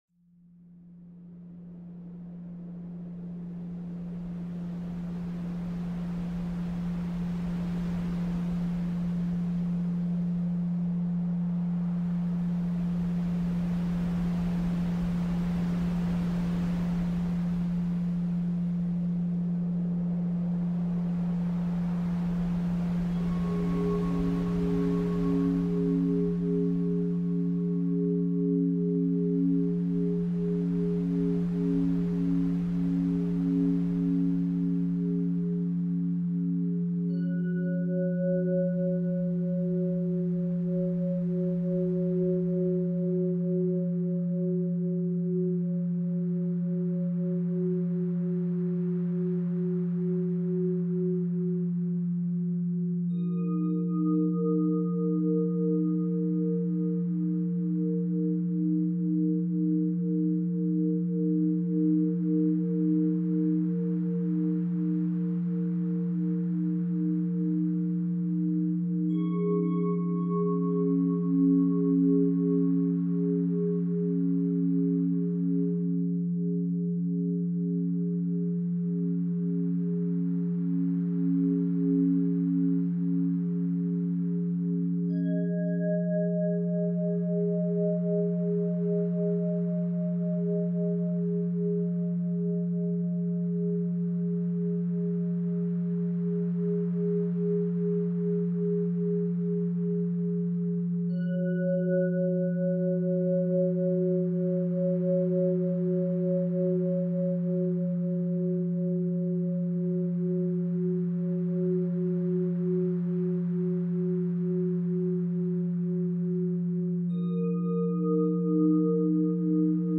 Inner Healing – 528 Hz for Physical and Emotional Restoration